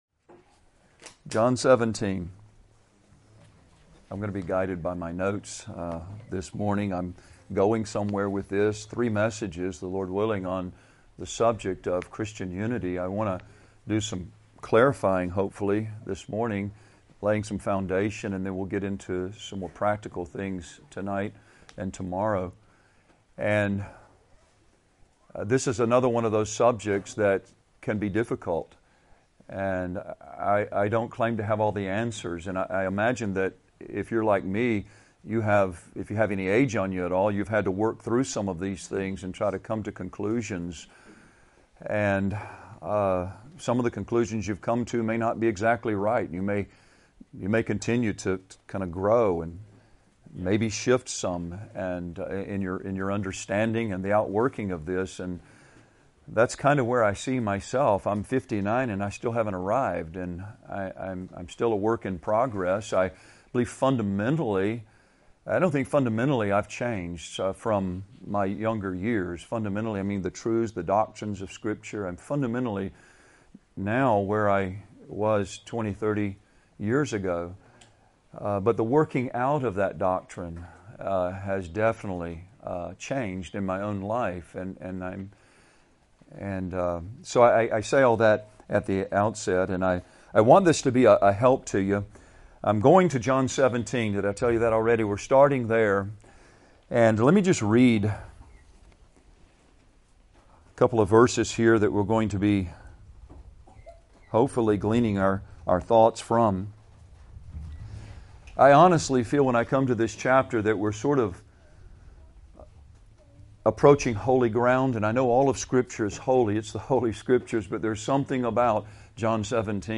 2018 Men's Retreat | 44:33 | Unity is foundational and essential to Christ's people.